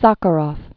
(säkə-rôf, săkə-, säə-rəf), Andrei Dimitrievich 1921-1989.